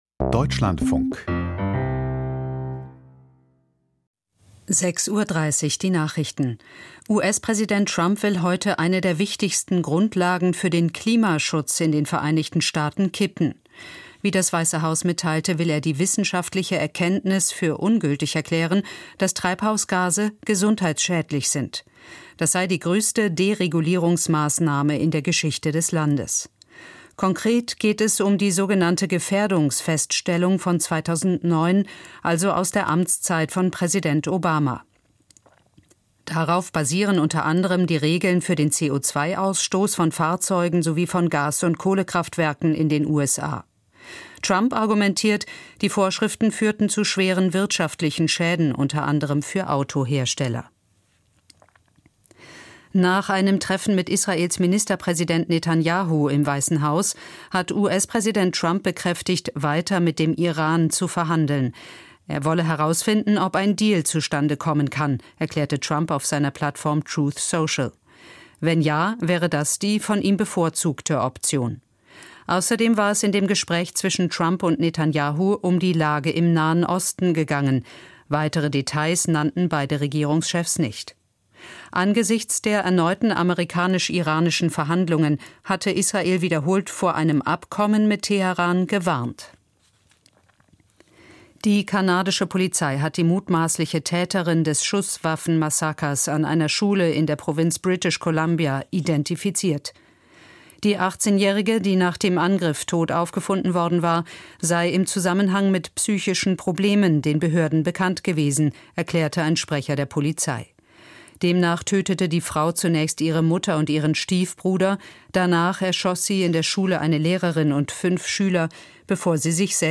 Die Nachrichten vom 12.02.2026, 06:30 Uhr
Aus der Deutschlandfunk-Nachrichtenredaktion.